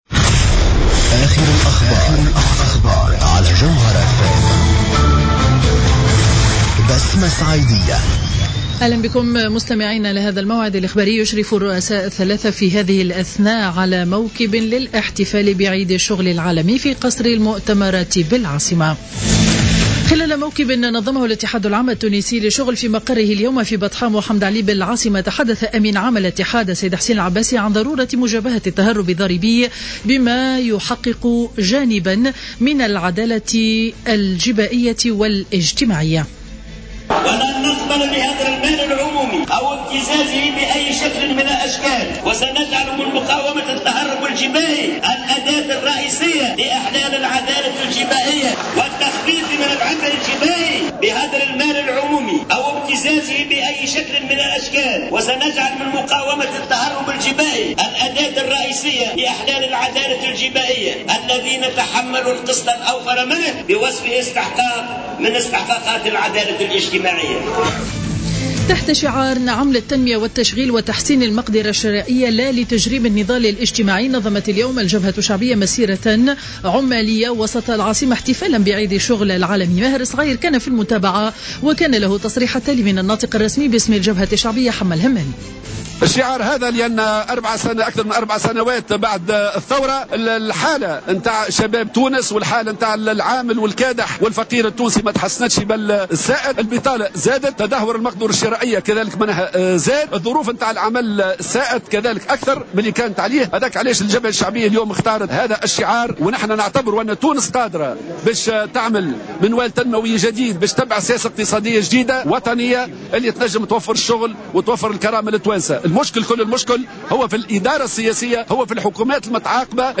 نشرة أخبار منتصف النهار ليوم الجمعة 01 ماي 2015